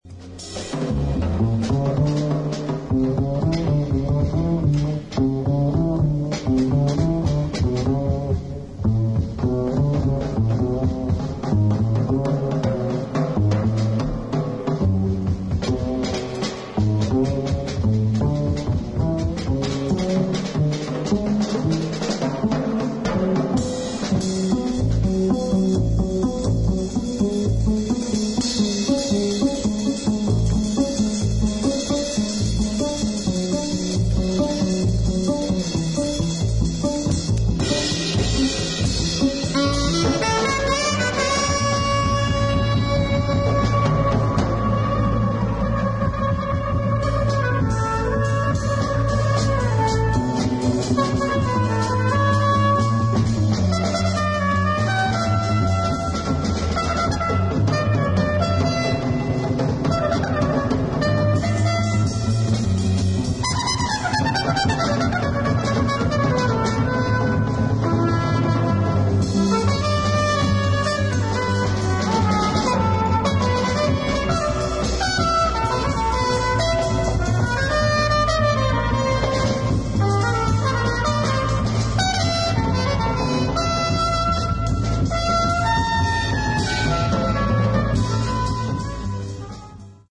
1969年にニューヨーク大学にて録音。
アルトサックス、トランペット、ヴァイオリン